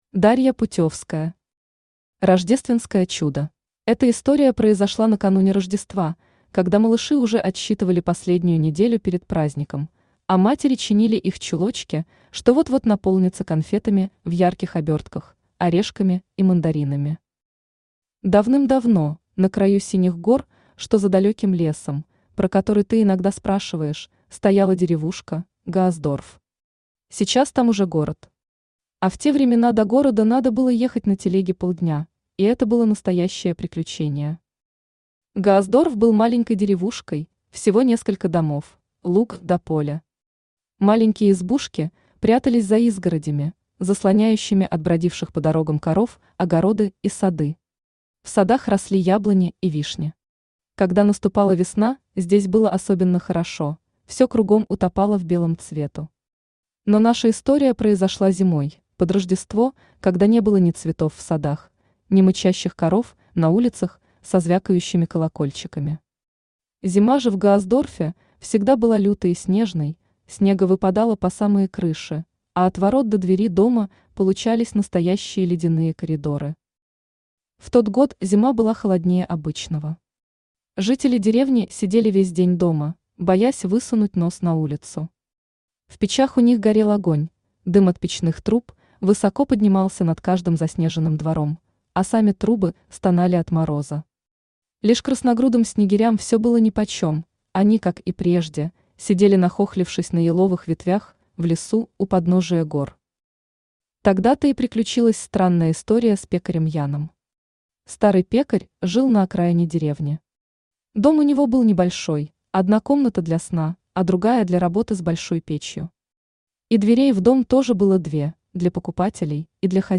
Аудиокнига Рождественское чудо | Библиотека аудиокниг
Aудиокнига Рождественское чудо Автор Дарья Валентиновна Путевская Читает аудиокнигу Авточтец ЛитРес.